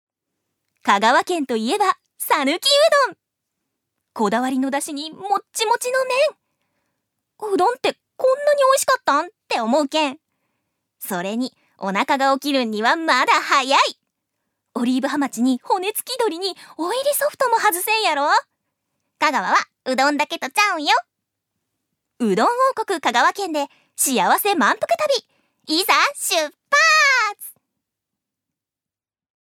預かり：女性
ナレーション１